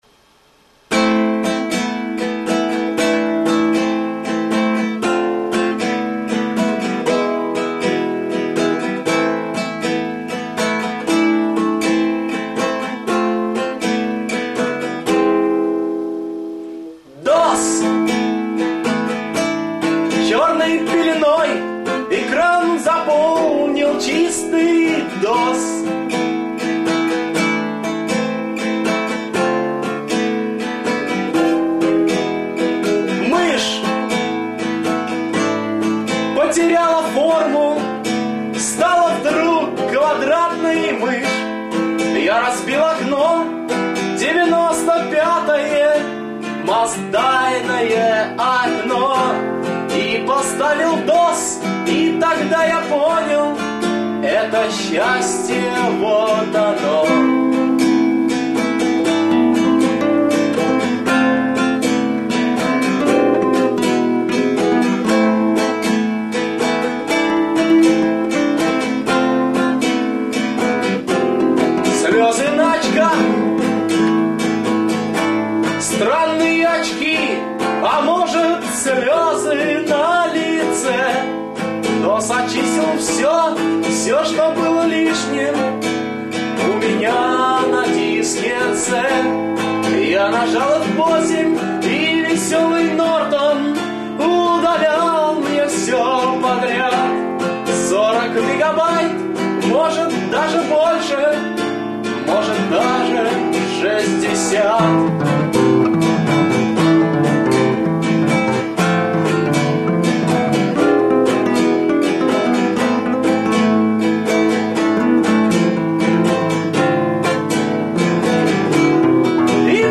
Песенка